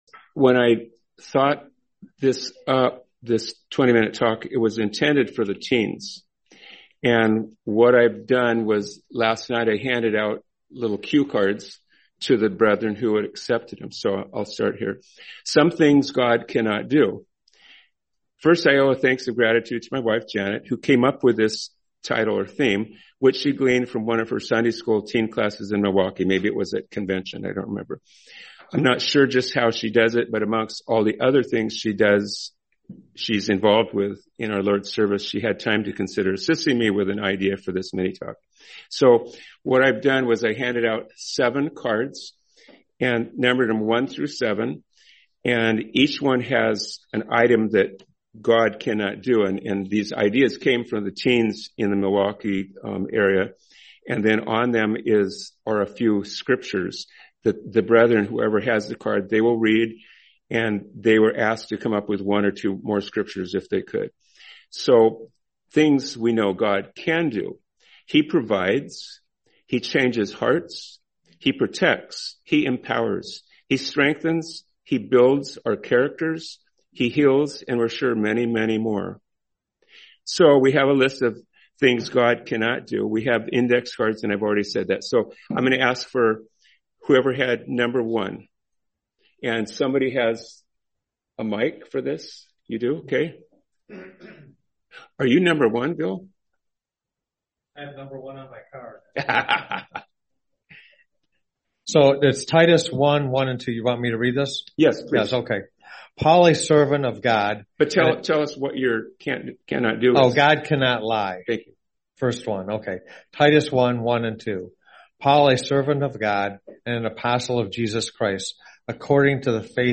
Series: 2025 Sacramento Convention